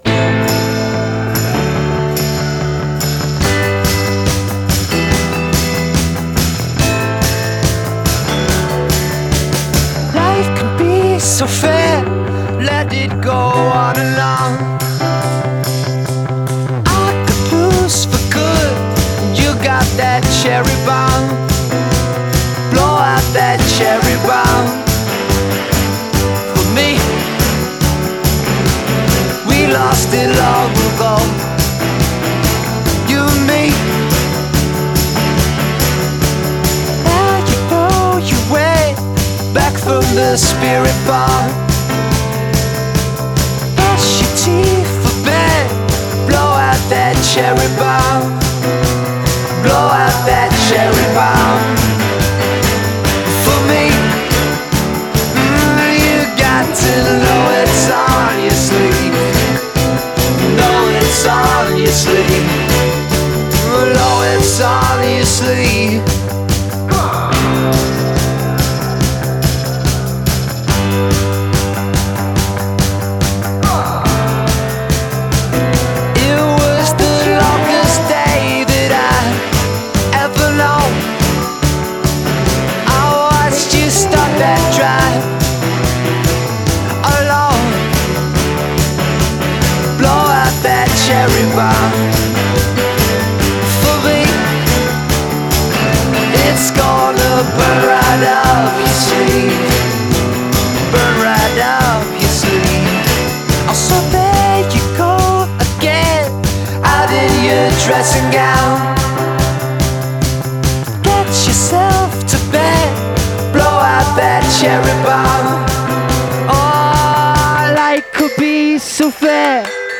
Featuring a New Wave meets Motown stylized arrangement
great melody, production, and a very danceable rhythm.